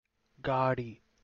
Captions English Kannada pronunciation of "gaadi"